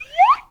whistle_slide_up_02.wav